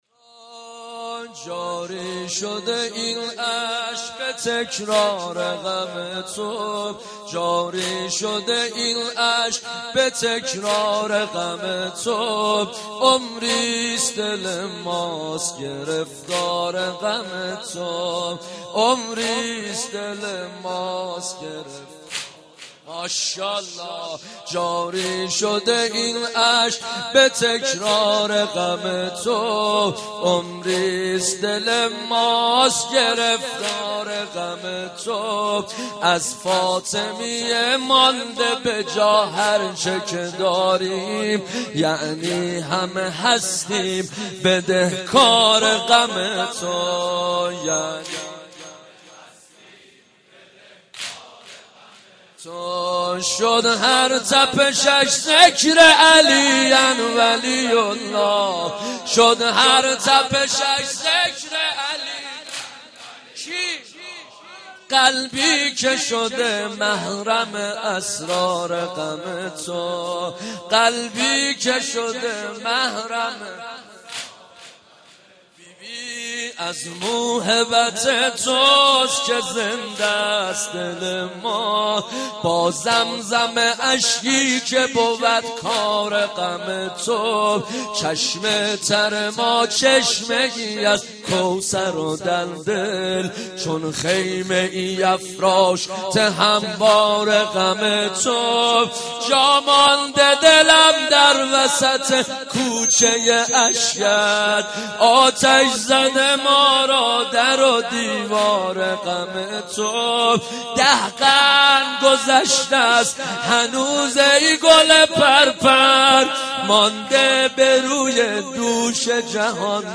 مداحی و نوحه
سینه زنی در شهادت ام ابیها، «حضرت زهرا(س